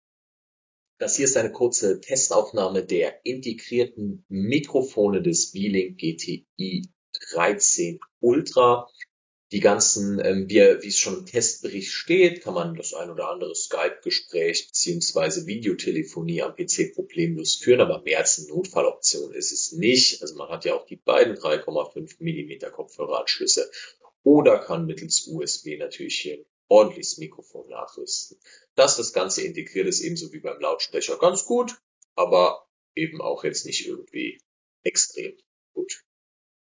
Noch ein kurzes Wort zu den Lautsprechern und dem Mikrofon. Ich hänge hier eine Testaufnahme an, die Qualität reicht in ruhigen Räumen gerade für Videotelefonie aus.